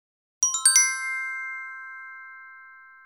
차임벨.wav